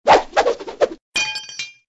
TL_rake_throw_only.ogg